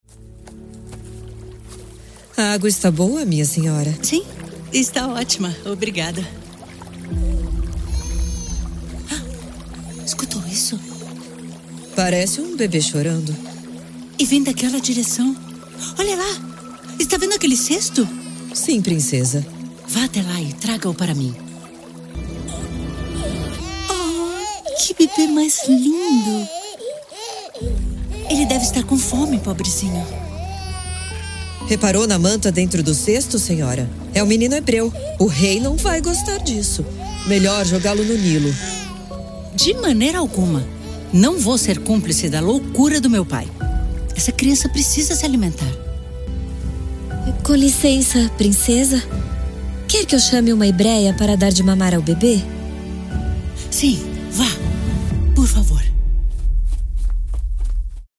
Je suis décrite comme une voix brésilienne chaleureuse et charmante.
Actuellement, mes fichiers audio sont enregistrés dans mon home studio à São Paulo, au Brésil, avec du matériel professionnel.
Mezzo-soprano